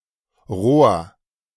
Rohr (German pronunciation: [ʁoːɐ̯]